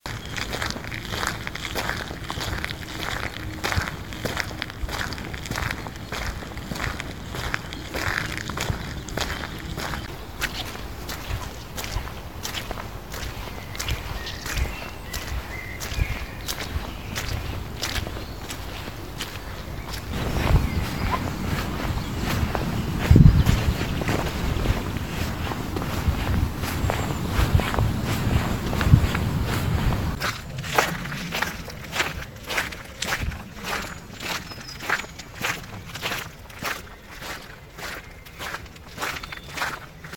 Auf der Basis der Wahrnehmung von eigenen Geräuschen der Fortbewegung wird das Zusammenspiel deutlich. Infolge verschiedener Landschaften und Beschaffenheiten des Naturschutzgebiets entstehen diverse Geräusche, welche durch die topografischen Gegebenheiten verstärkt werden. Zu hören sind die Wegebeschaffenheiten von Asphalt, Wald, Sand sowie Kies.
Zieht man einen Vergleich von Stadt und Natur, so führt die vergleichsweise mangelnde Geräuschkulisse eines Naturraums im Zusammenspiel mit diesen selbst erzeugten Geräuschen zu einer zum Teil befremdlichen Atmosphäre.